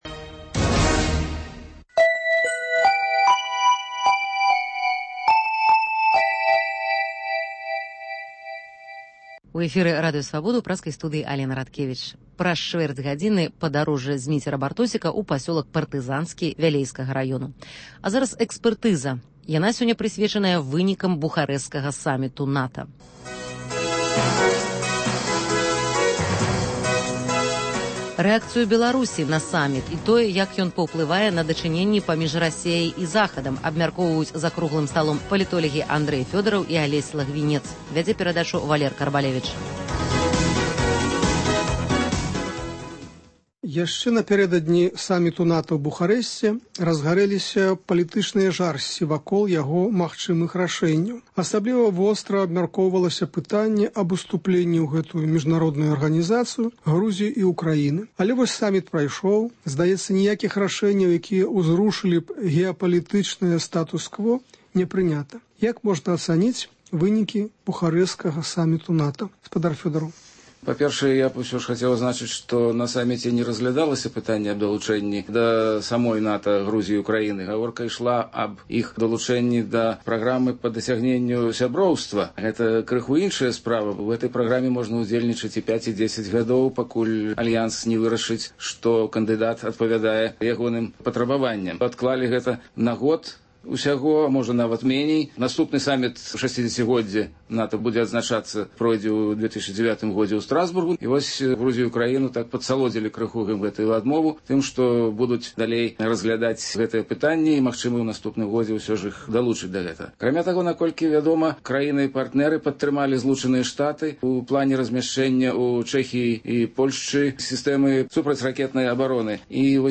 Госьць у студыі адказвае на лісты, званкі, СМСпаведамленьні